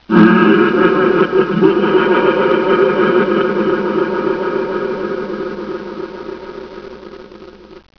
pain100_2.wav